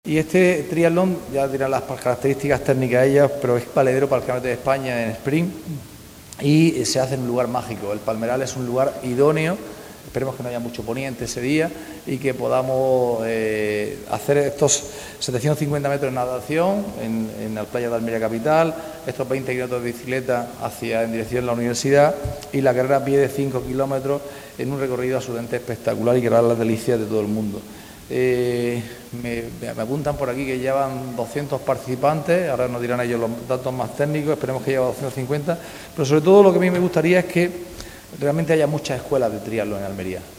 ANTONIO-JESUS-CASIMIRO-CONCEJAL-CIUDAD-ACTIVA-TRIATLON.mp3